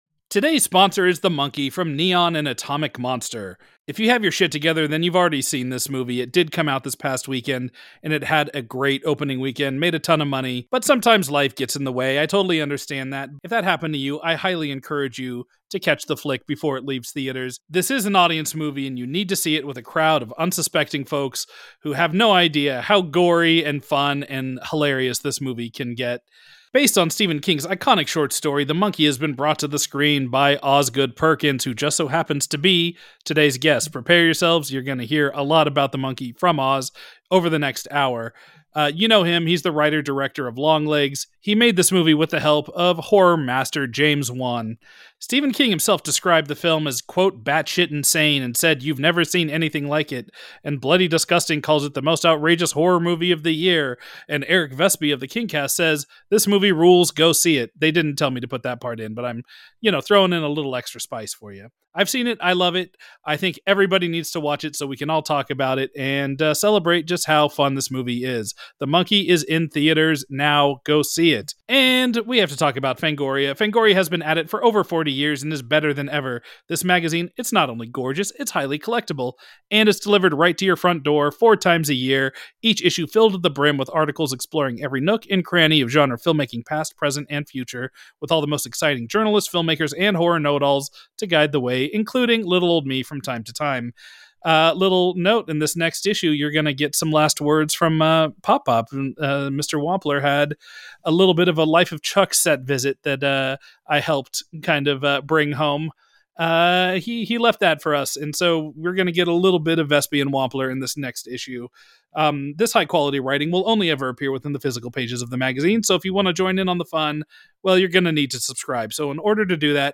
Osgood Perkins joins The Kingcast crew to talk about his adaptation of Stephen King's short story The Monkey as well as his personal relationship with the randomness of death. It's a lively chat that digs into Perkins's early memories of King, how he bonded with his famous father over King's books, and how King's humor really impacted how he approached this wild, wild movie.